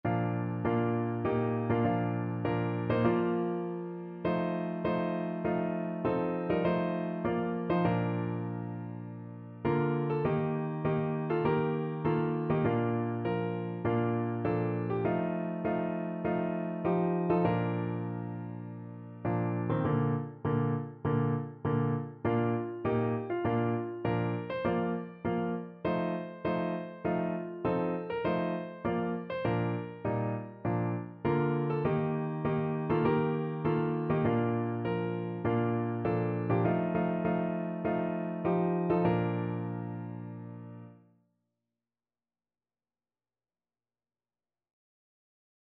No parts available for this pieces as it is for solo piano.
4/4 (View more 4/4 Music)
Piano  (View more Intermediate Piano Music)
Christian (View more Christian Piano Music)